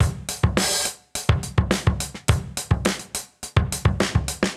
Index of /musicradar/sampled-funk-soul-samples/105bpm/Beats